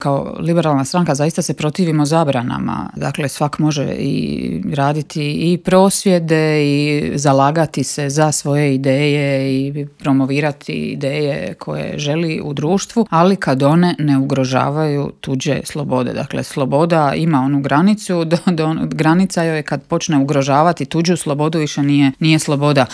ZAGREB - U Intervjuu Media servisa ugostili smo saborsku zastupnicu i splitsku gradsku vijećnicu Centra Marijanu Puljak.